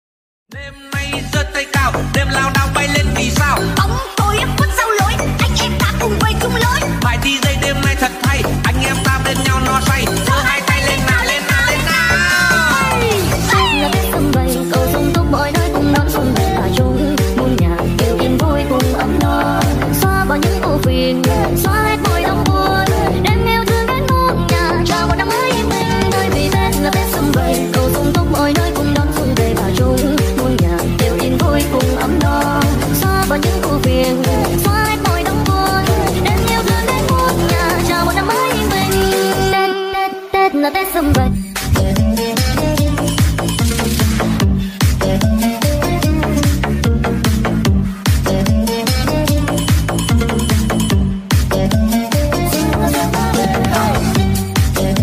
Nhạc Tiktok 3 lượt xem 14/03/2026